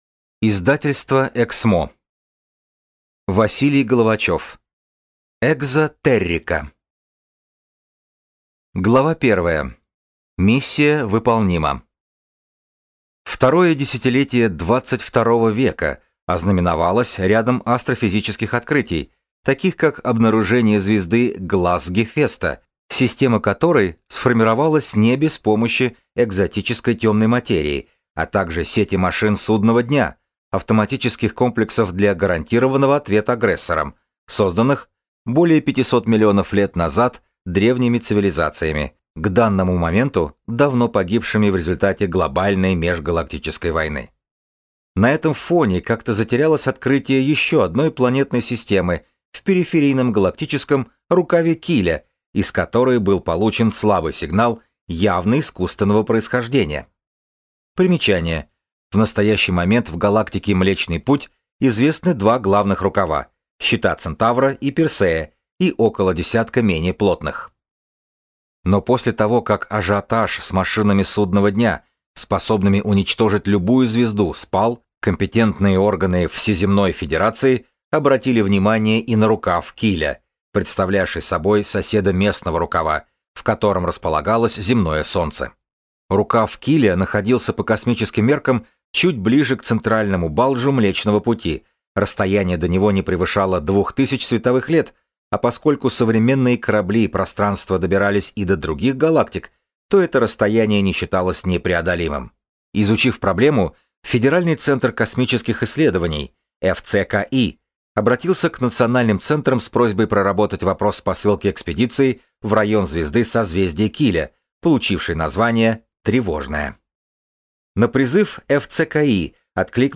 Аудиокнига Экзотеррика | Библиотека аудиокниг